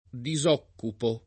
disoccupare v.; disoccupo [